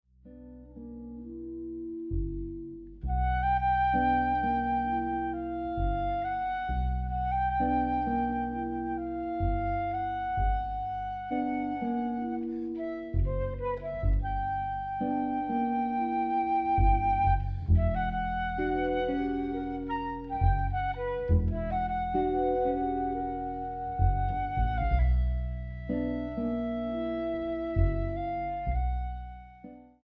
bandoneón